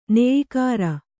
♪ neyikāra